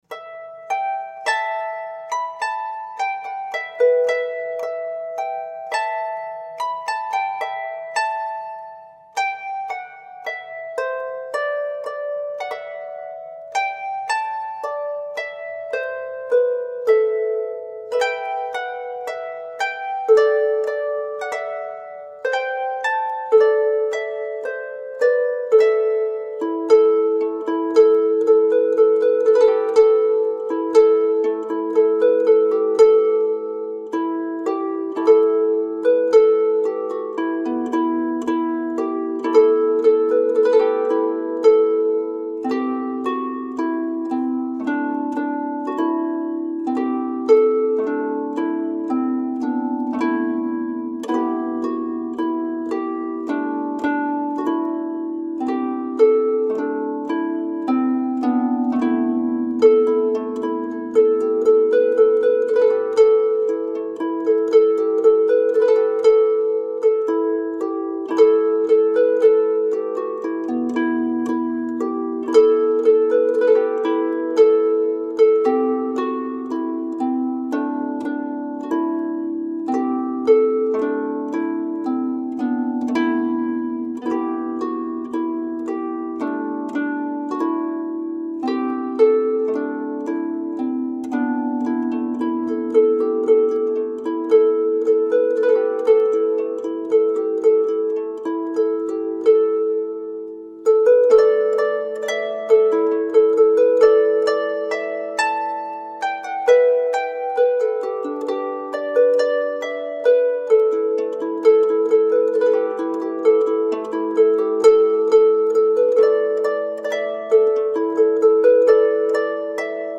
Medieval and middle eastern music.
traditional Scottish